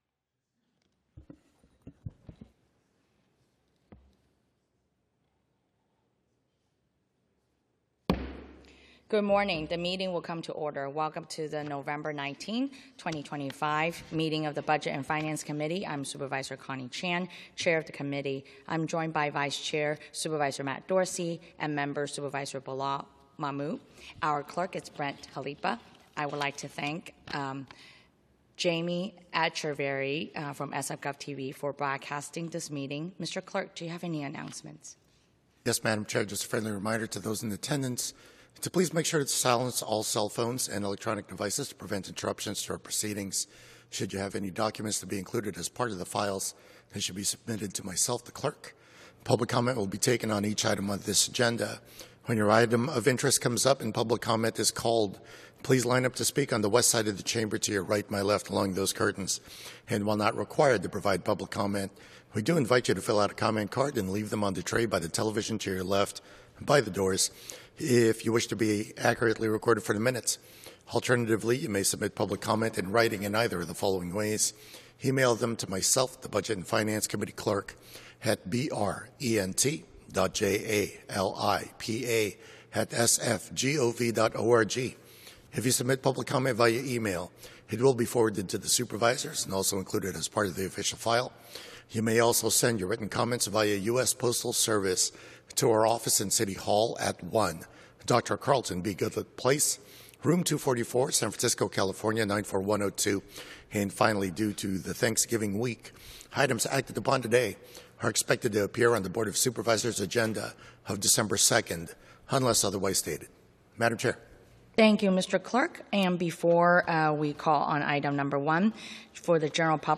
BOS Budget and Finance Committee - Regular Meeting - Nov 19, 2025